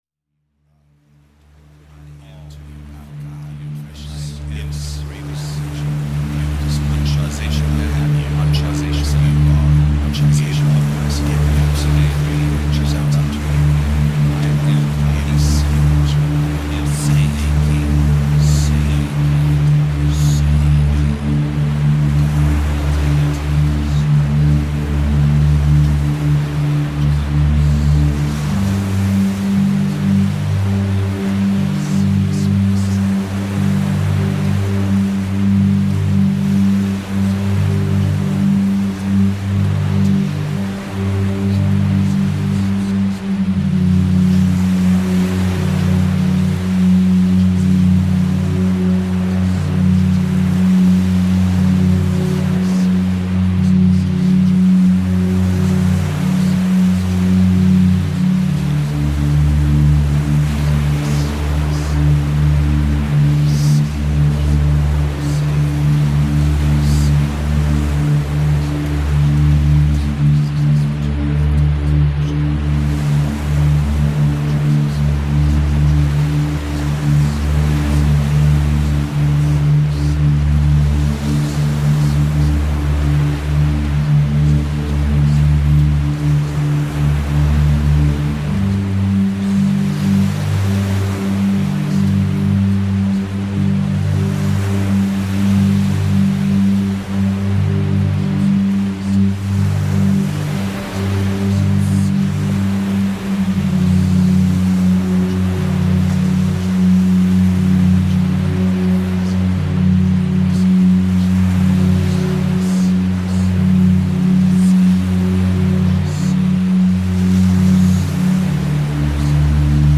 3 subliminal tracks,
With Isochronic Tones